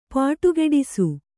♪ pāṭugeḍisu